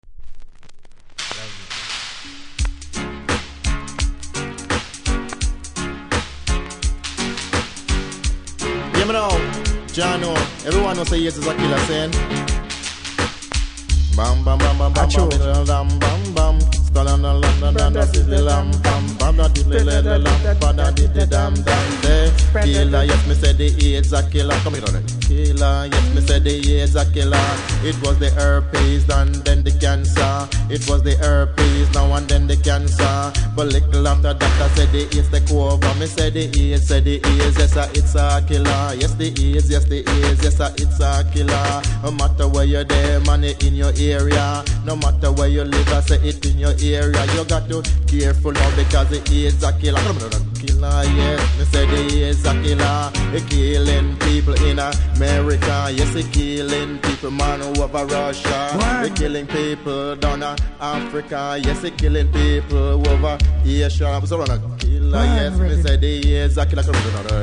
多少うすキズありますが音は良好なので試聴で確認下さい。